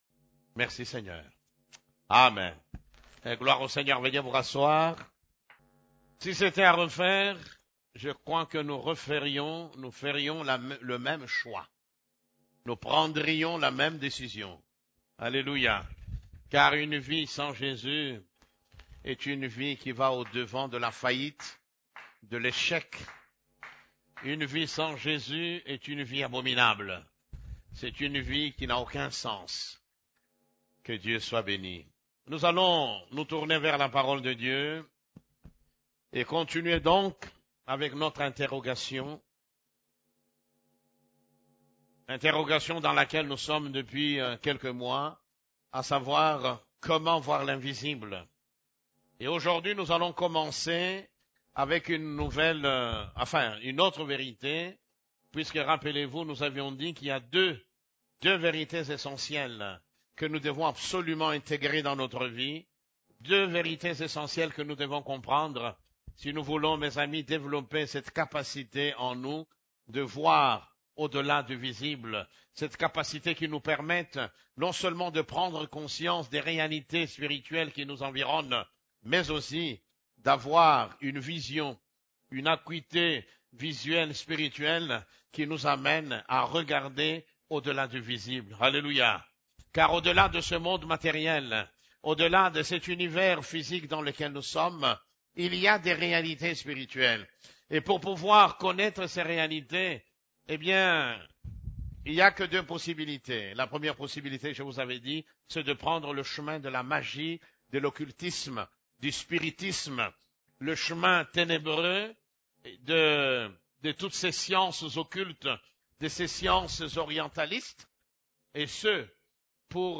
CEF la Borne, Culte du Dimanche, Comment voir l'invisible: Le renoncement à soi-même (12)